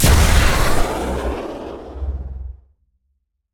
CosmicRageSounds / ogg / ships / combat / weapons / pmf.ogg